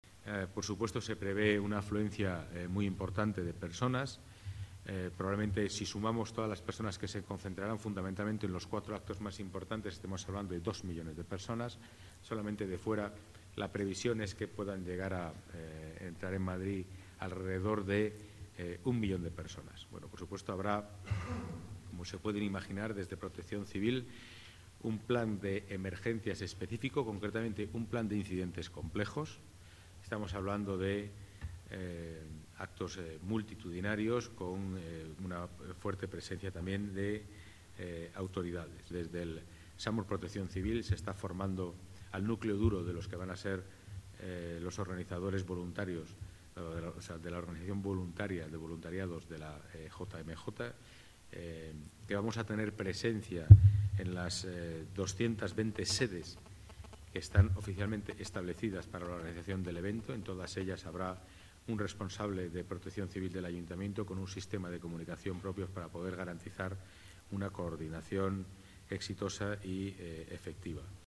Nueva ventana:Declaraciones del delegado de Seguridad, Pedro Calvo